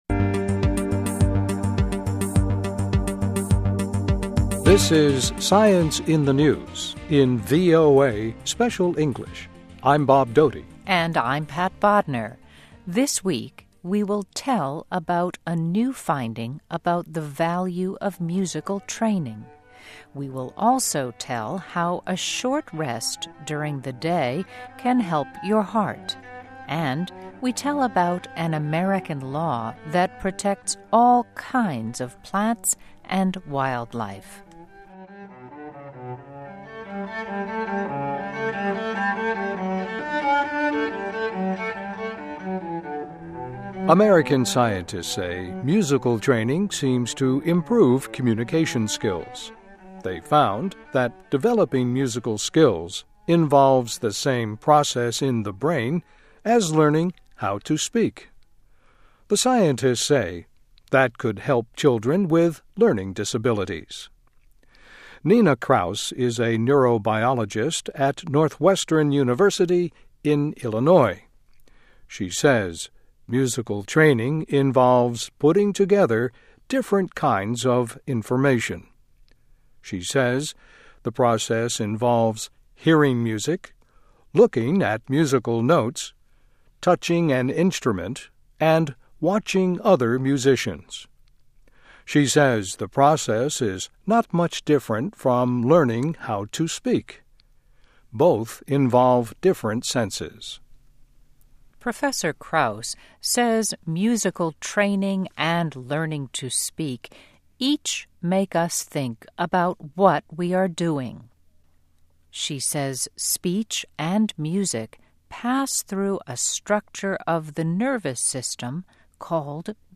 This is SCIENCE IN THE NEWS in VOA Special English.